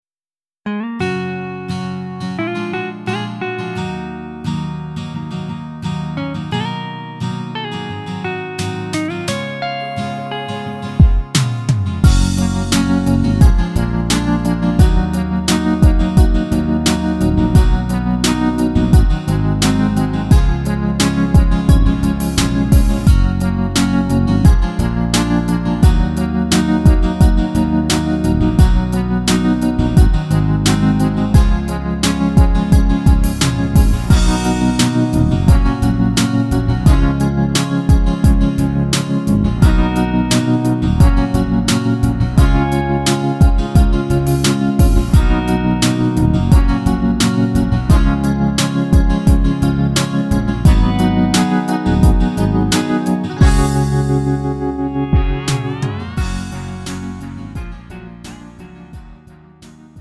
음정 -1키 2:30
장르 가요 구분 Lite MR